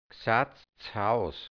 Mundart-Wörter | Mundart-Lexikon | hianzisch-deutsch | Redewendungen | Dialekt | Burgenland | Mundart-Suche: G Seite: 14